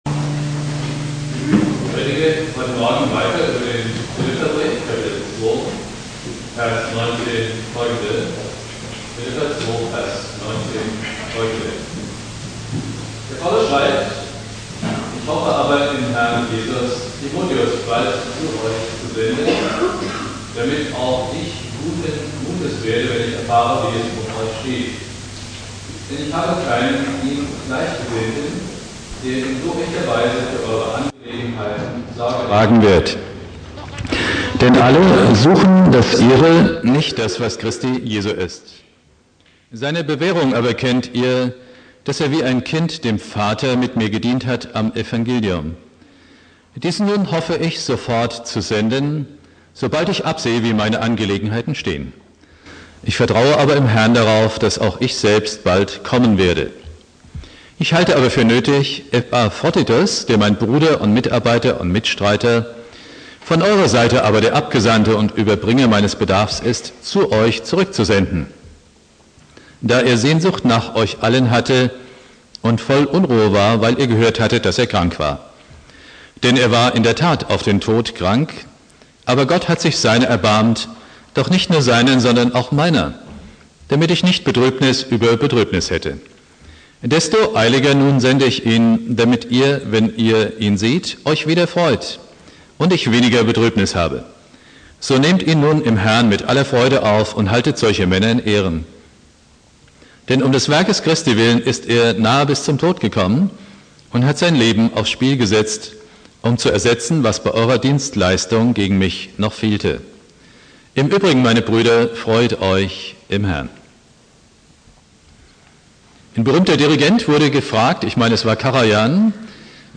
Predigt
(schlechte Aufnahmequalität) Bibeltext: Philipper 2,19-30 Dauer